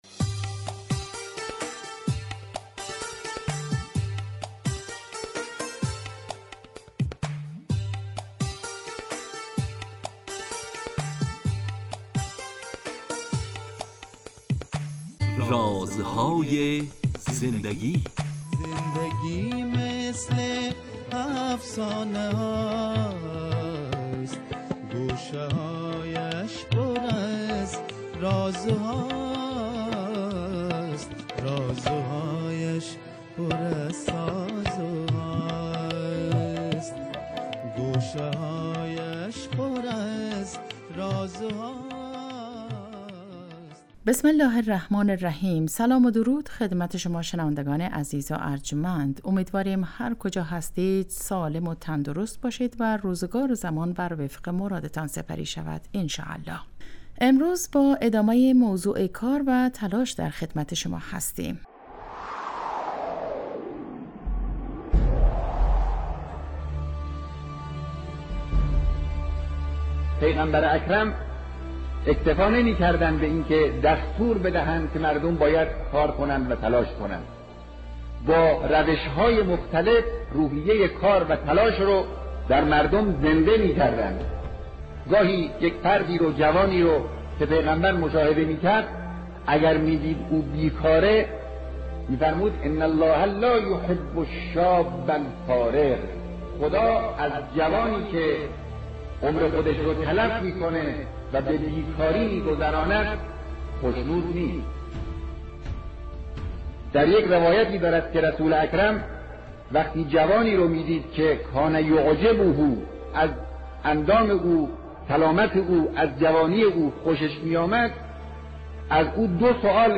با مجموعه برنامه " رازهای زندگی" و در چارچوب نگاهی دینی به سبک زندگی با شما هستیم. این برنامه به مدت 15 دقیقه هر روز ساعت 11:35 به وقت افغانستان از رادیو دری پخش می شود .